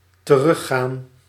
Ääntäminen
IPA: /təˈrʏxaːn/